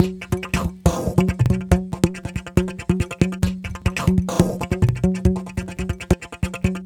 World Drumz 140.wav